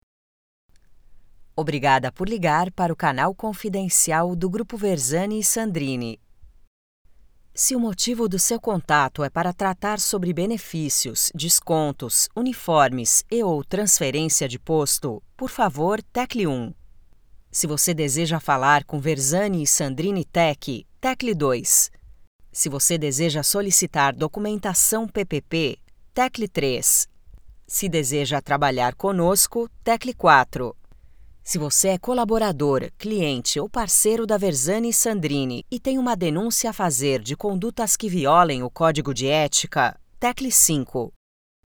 Natural, Versatile, Warm
Telephony